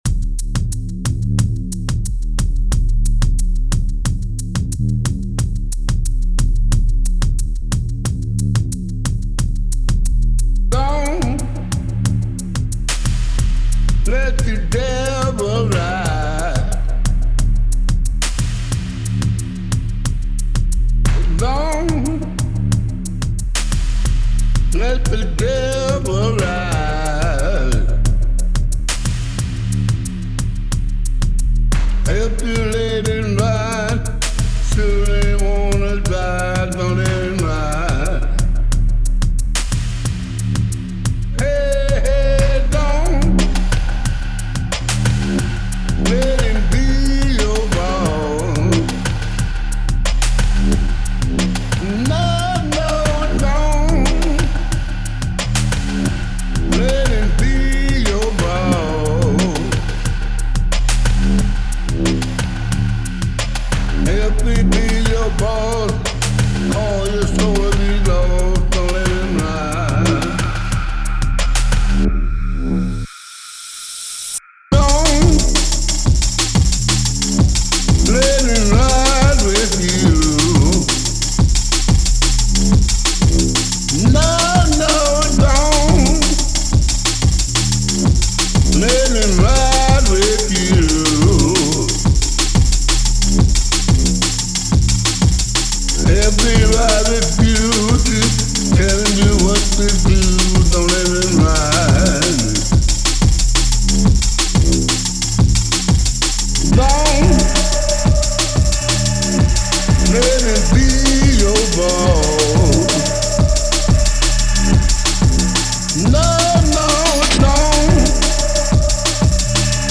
Literally blown away, and thank you all for the kind words and taking time to contribute, tbh I wasn’t even going to submit a beat and this track was the result of a pretty crappy day, so yeah sometimes out of the ugliness something good appears, anyhoo I have to say I actually realised after the playlist was up that the vocal was horribly pitched compared to the track which has bugged me no end even though none of you seem to have been bothered by it but I’ve fixed it in the days since and bumped the BPM a bit to lessen some of the artifacts in the vocal due to the time stretching…